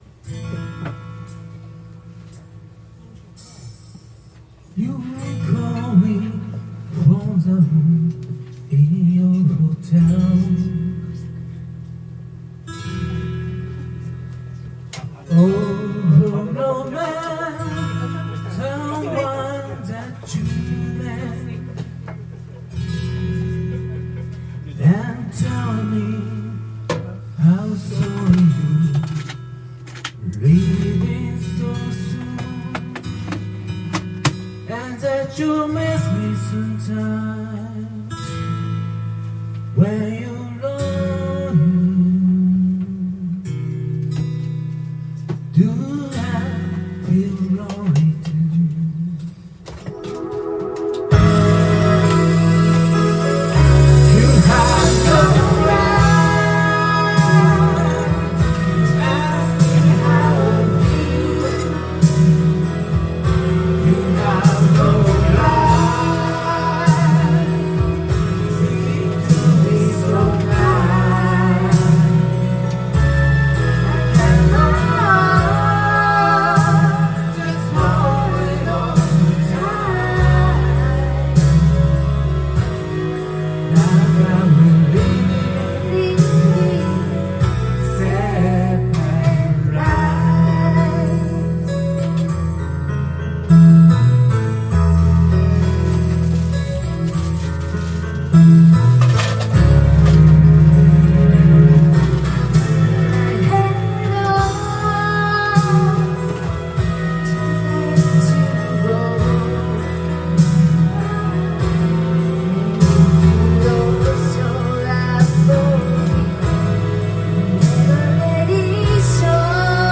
Duet & Chorus Night Vol. 18 TURN TABLE